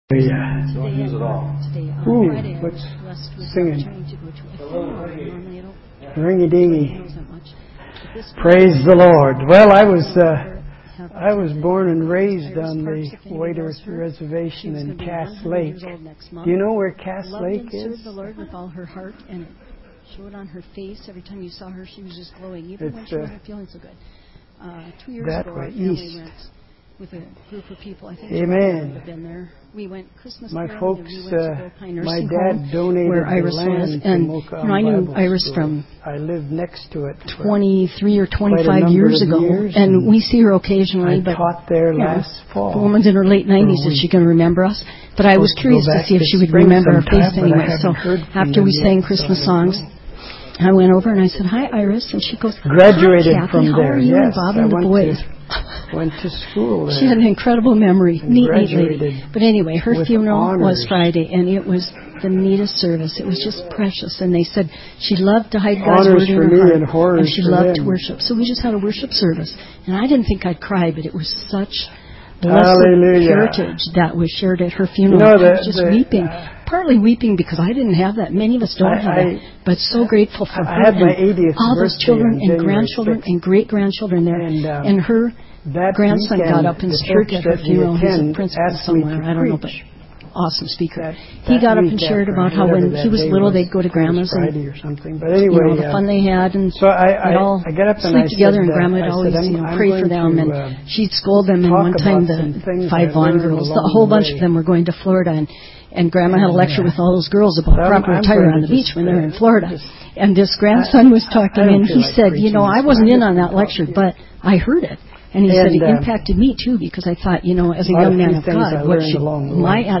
This entry was posted in sermons .